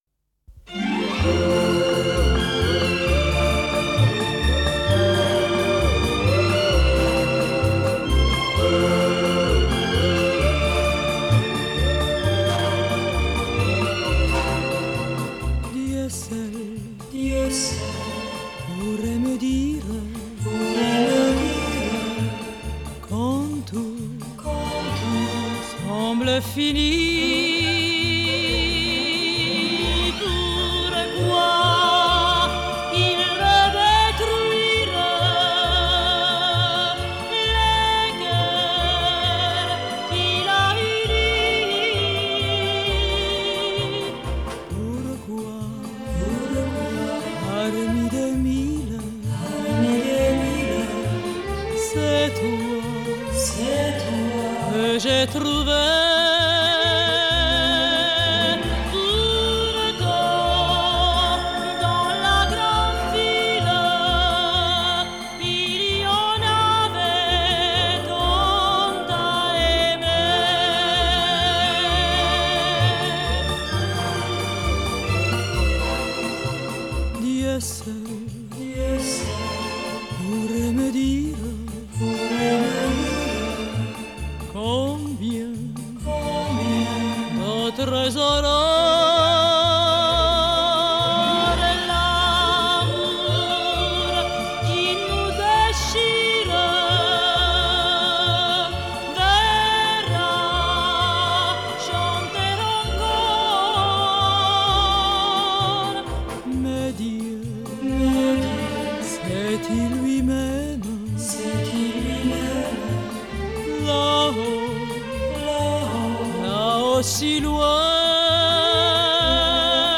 Genre: Variete Francaise, Pop Vocale